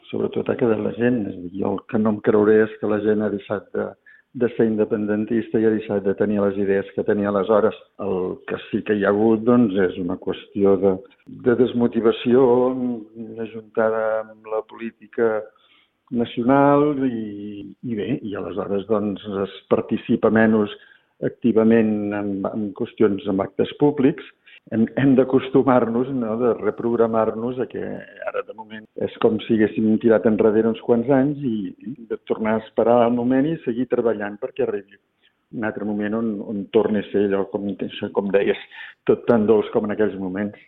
en una entrevista a RCT.